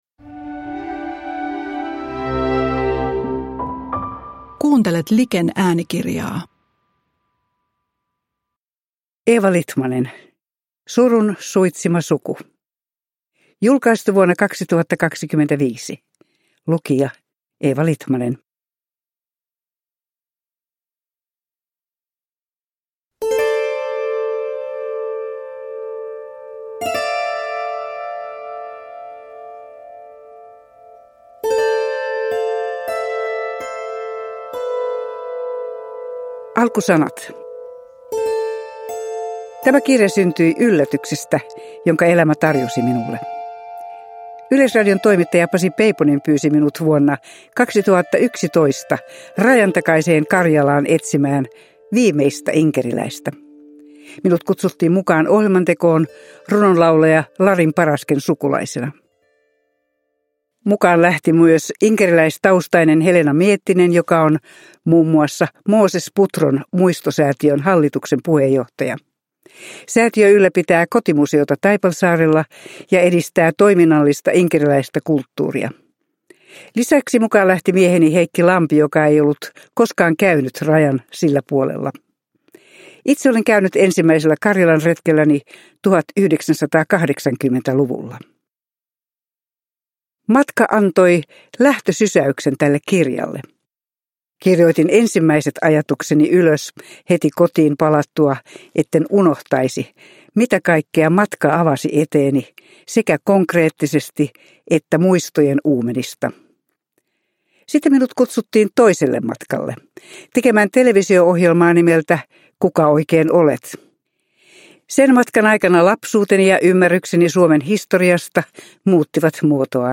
Surun suitsima suku – Ljudbok